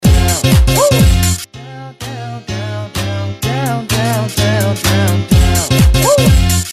Знаете как некоторые девушки кричат ОУ!!
Эдакие крик адреналина. Высокий. На лай собаки похож.